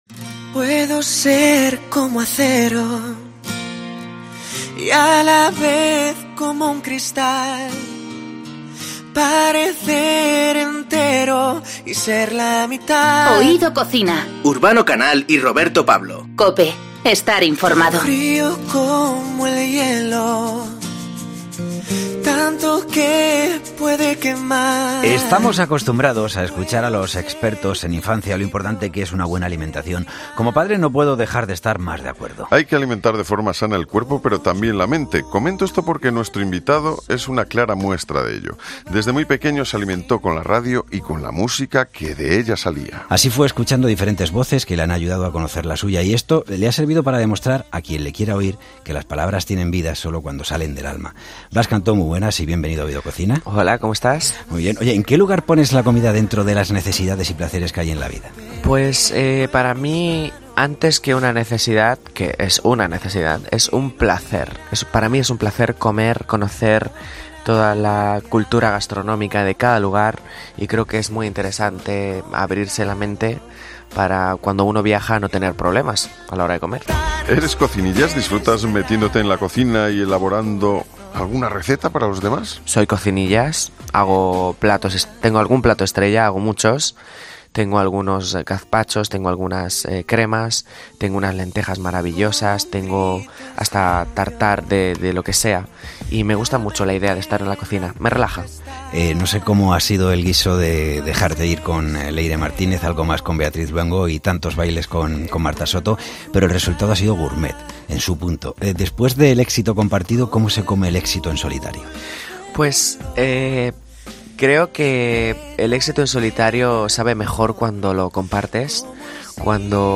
Una charla de lo más amena en la que Blas Cantó nos demuestra que las canciones también pueden tener sabores.